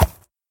mob / horse / soft2.ogg
should be correct audio levels.